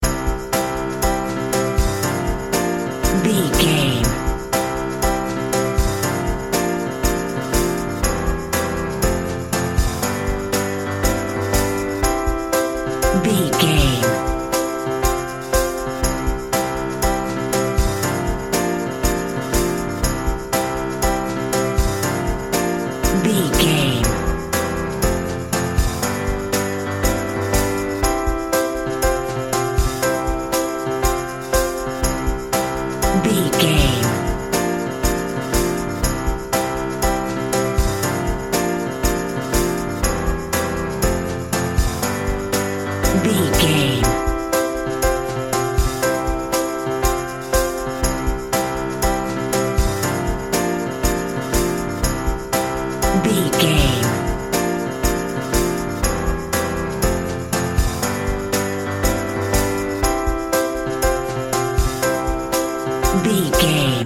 Ionian/Major
Lounge
chill out
easy listening
laid back
relaxed
nu jazz
downtempo
synth lead
synth bass
synth drums